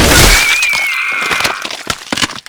pop.wav